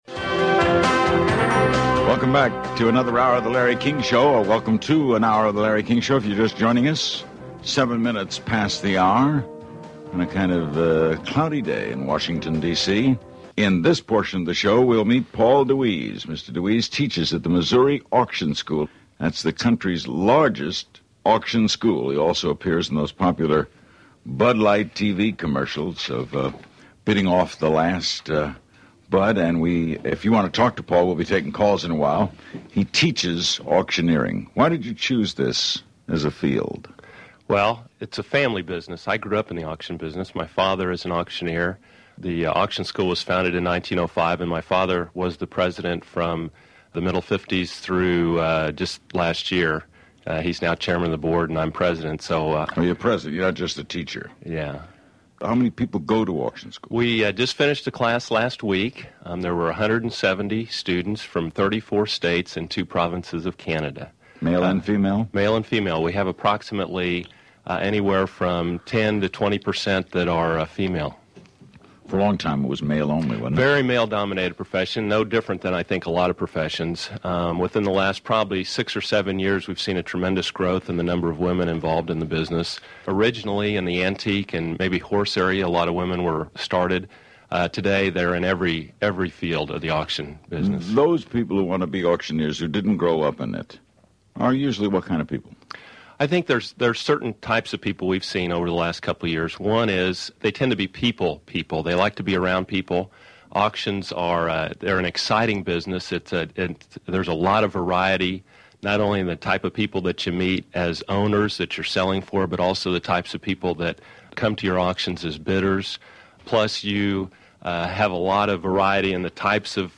The Larry King Interview, Part 1